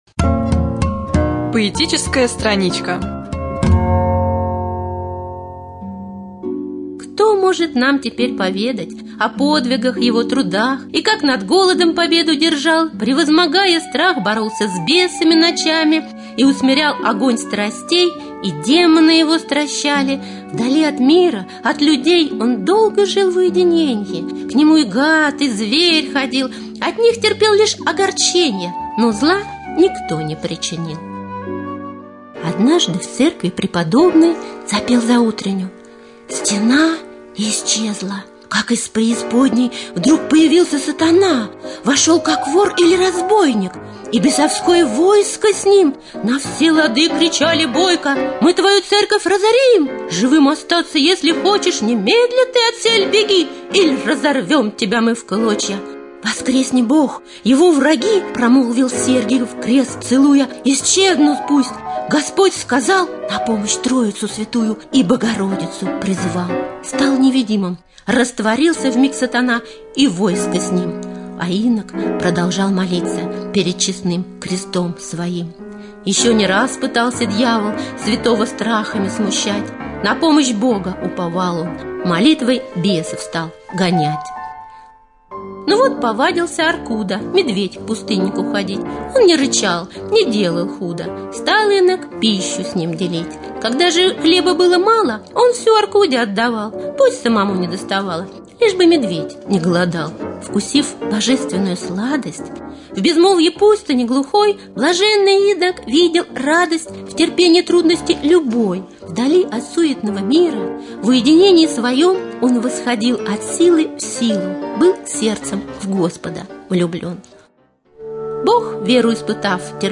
5.Рубрика «Поэтическая страничка».